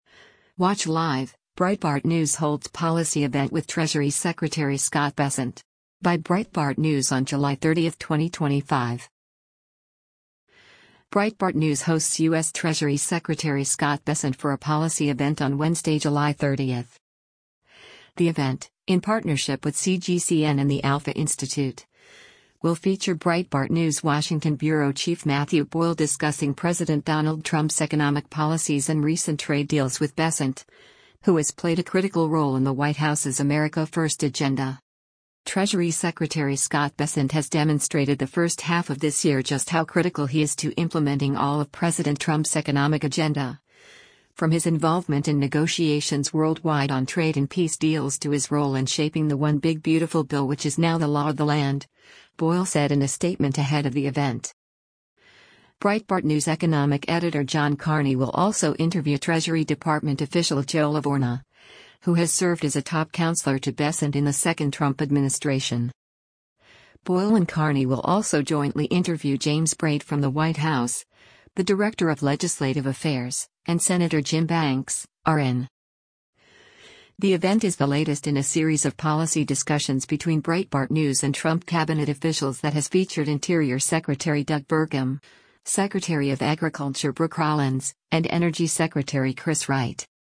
Watch Live: Breitbart News Holds Policy Event with Treasury Secretary Scott Bessent
Breitbart News hosts U.S. Treasury Secretary Scott Bessent for a policy event on Wednesday, July 30.